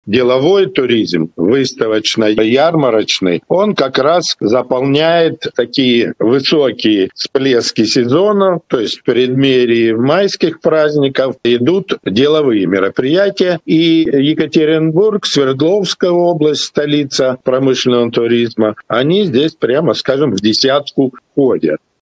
на пресс-конференции «Интерфакс-Урал».